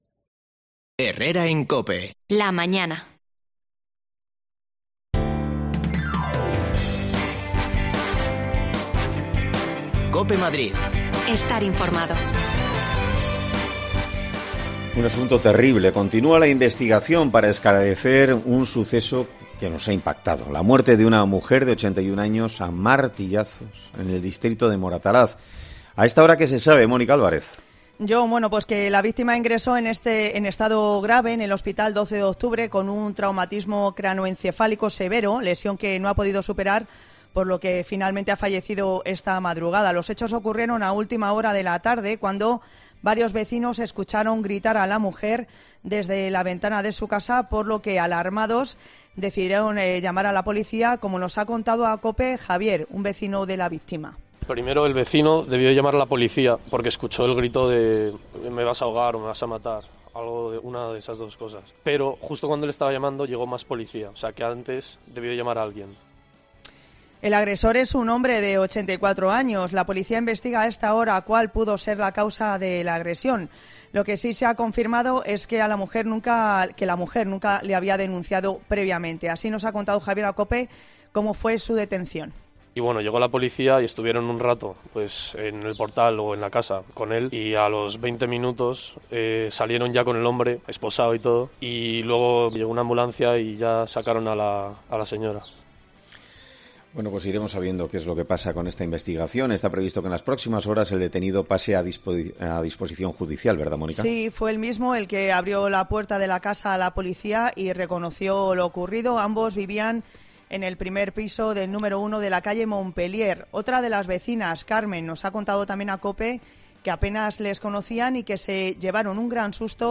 La policia investiga la muerte de una mujer de 81 años en el barrio de Moratalaz a manos de su marido. Hablamos con los vecinos de la zona
Las desconexiones locales de Madrid son espacios de 10 minutos de duración que se emiten en COPE , de lunes a viernes.